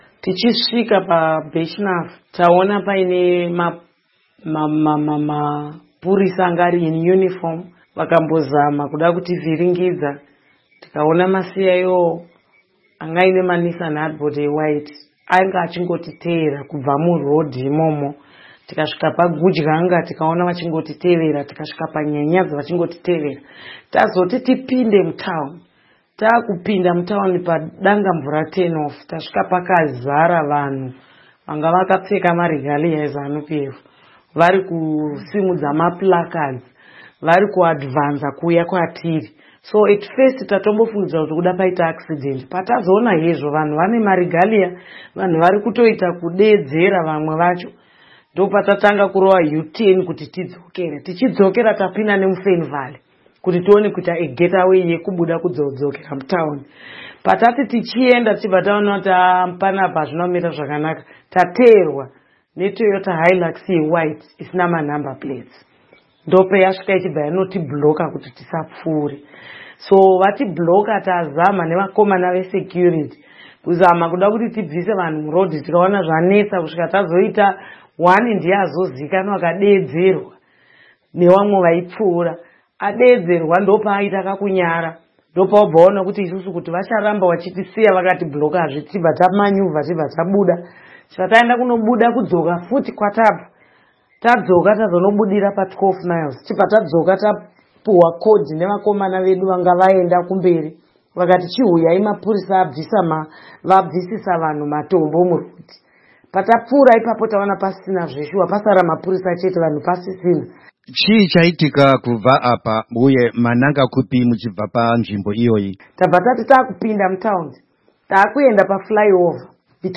Hurukuro naAmai Linette Karenyi-Kore